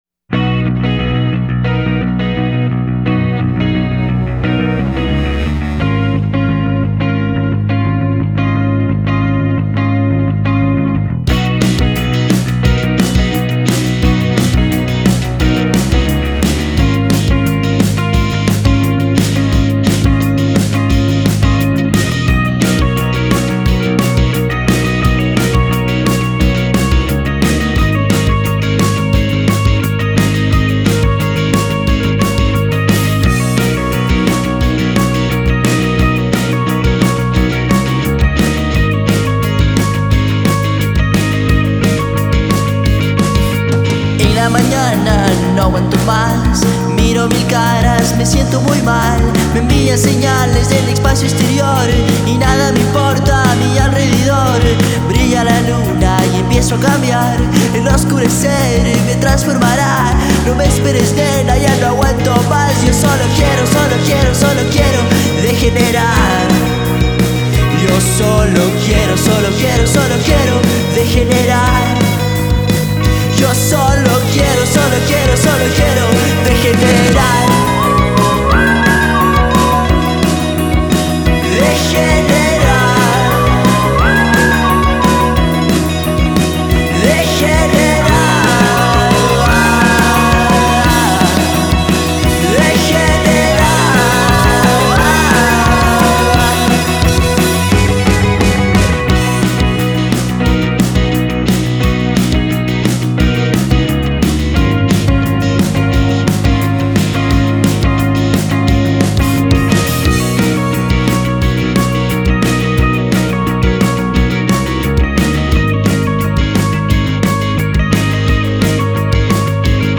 Guitarra y Voz
Bajo
Batería
Sintetizadores